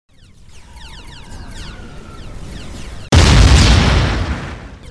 boom01.mp3